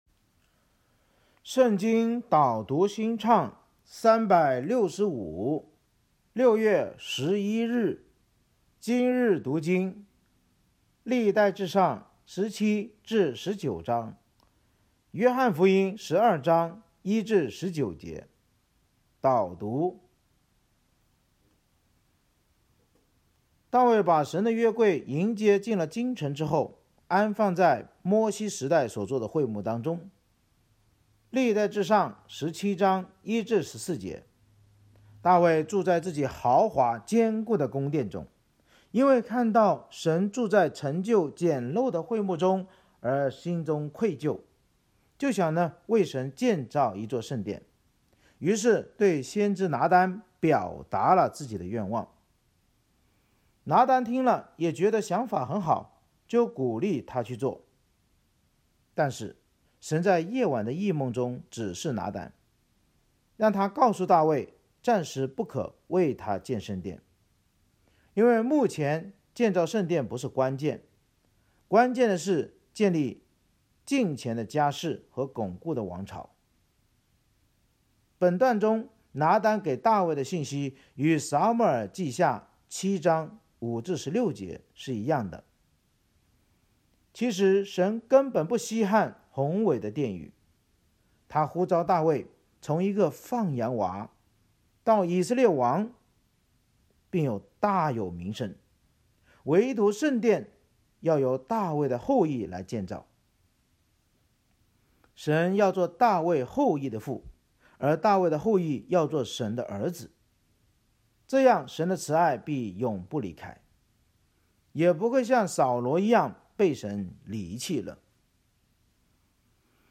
圣经导读&经文朗读 – 06月11日（音频+文字+新歌）